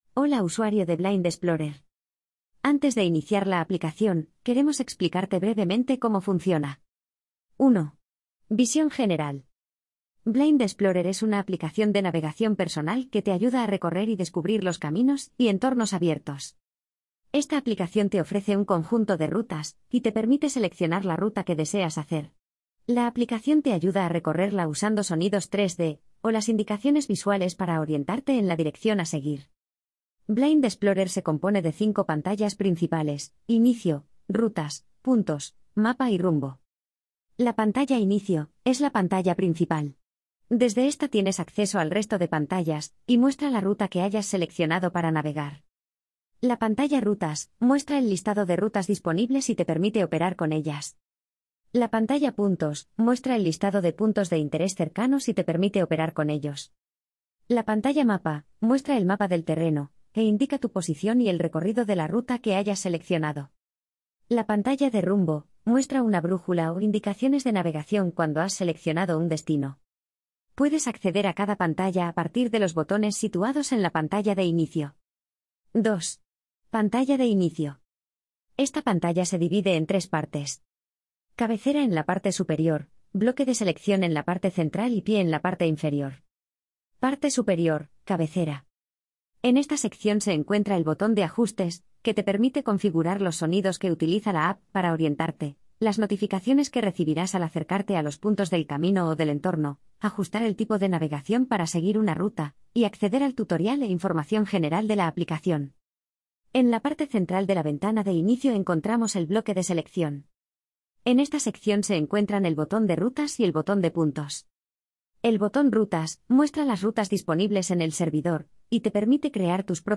ANEXO-III-Audioguia-manual-Blind-Explorer.mp3